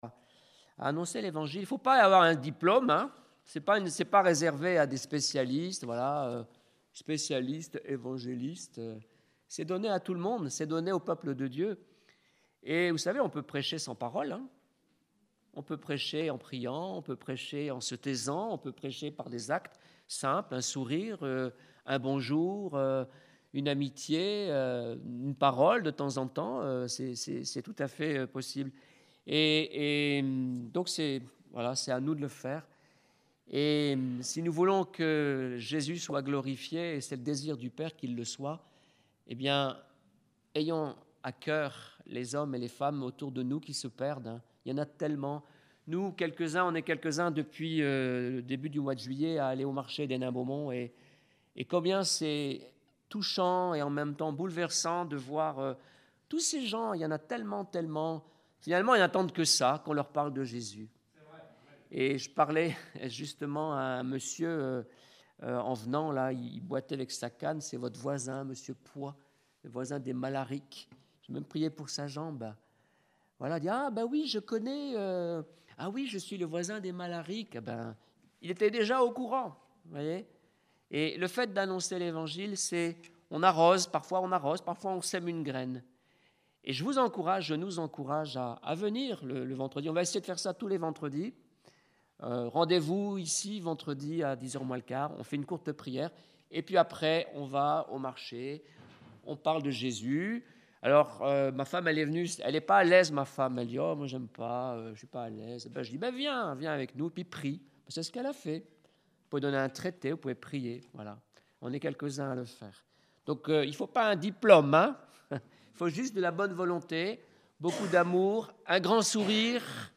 Prédications Fortifiez-vous